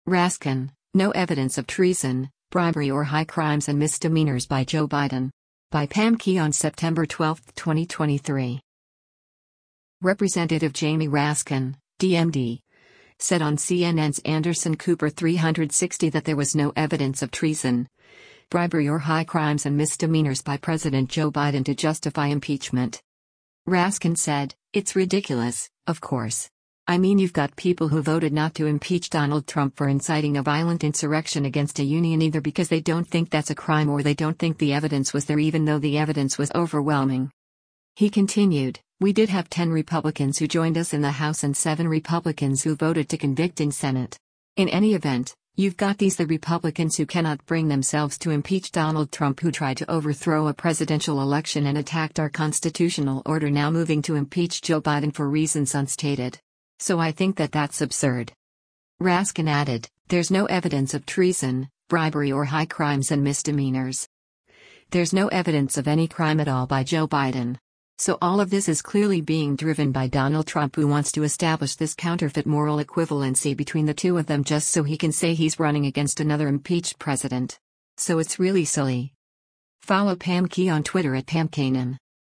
Representative Jamie Raskin (D-MD) said on CNN’s “Anderson Cooper 360” that there was “no evidence of treason, bribery or high crimes and misdemeanors” by President Joe Biden to justify impeachment.